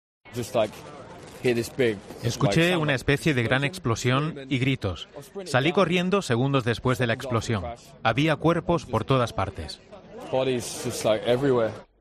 Un testigo cuenta cómo ha sido el ataque ocurrido en el centro de Melbourne